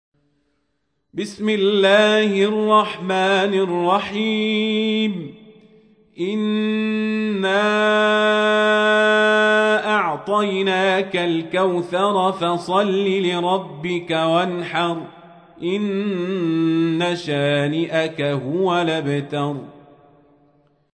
تحميل : 108. سورة الكوثر / القارئ القزابري / القرآن الكريم / موقع يا حسين